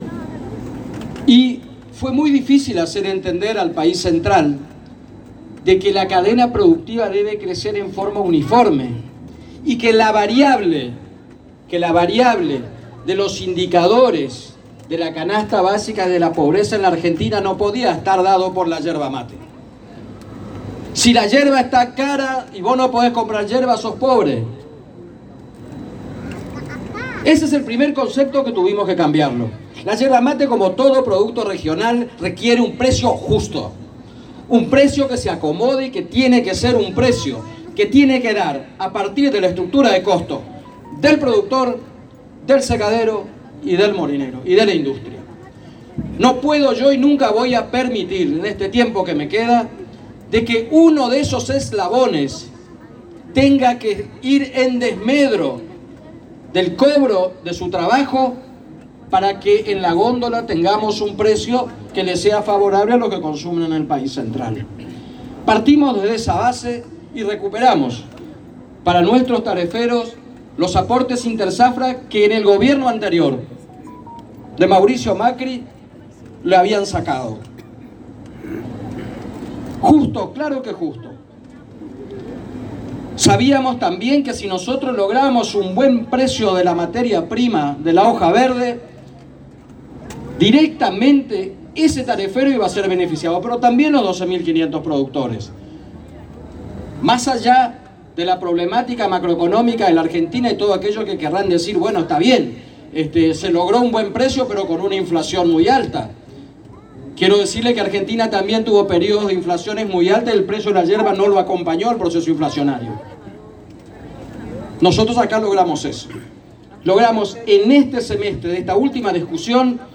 En la Inauguración de la 45 Fiesta Nacional e Internacional de la Yerba Mate y en su último discurso en este período como Gobernador de Misiones el Dr. Oscar Herrera Ahuad agradeció a la Intendente María Eugenia Safrán calificandola como amiga, por permitirle compartir con ella, el equipo de trabajo, la familia yerbatera y todo el pueblo este evento tan importante.